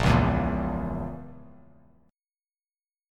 A#dim Chord